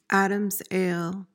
PRONUNCIATION:
(ad-uhmz AYL)